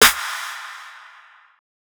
BWB WMH SNARE (36).wav